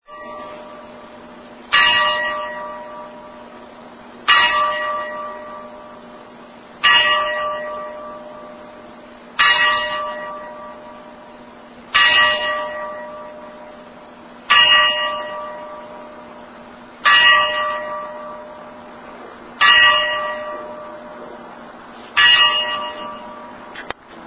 Single Ring (Dong – Dong)